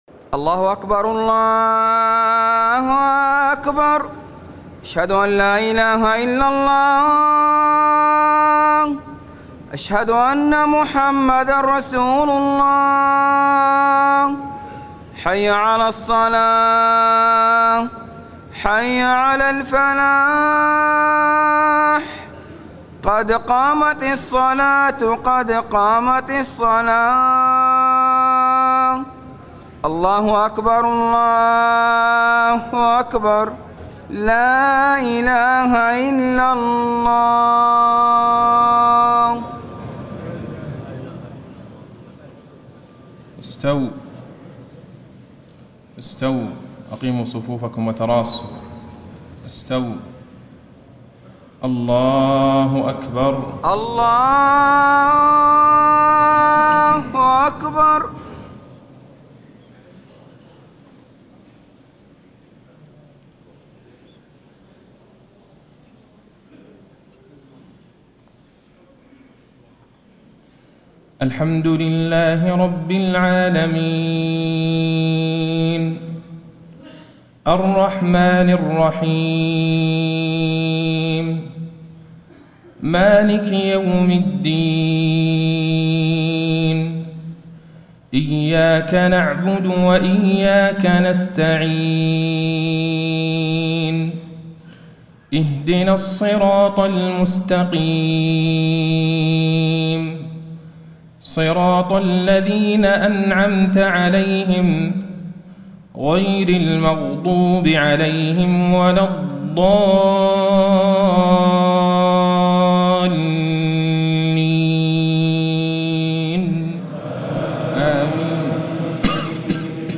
صلاة المغرب ٧ ربيع الأول ١٤٣٥هـ فواتح سورة المؤمنون 1-16 > 1435 🕌 > الفروض - تلاوات الحرمين